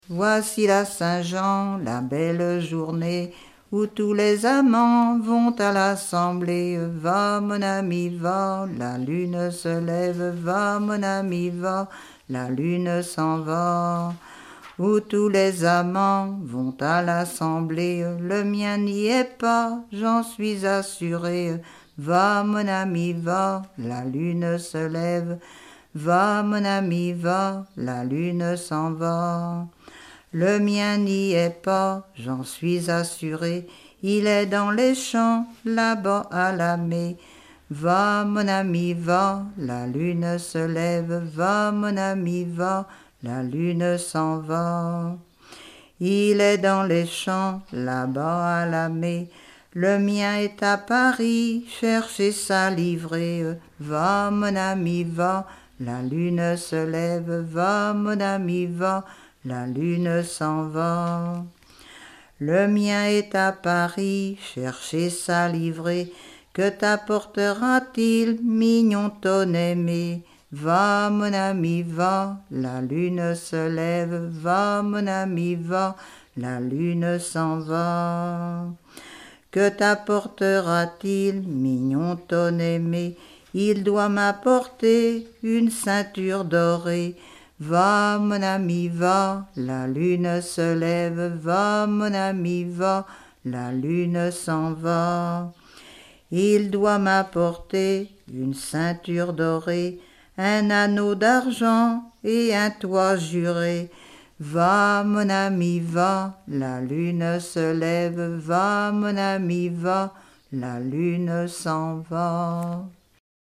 Genre laisse
chanson
Pièce musicale inédite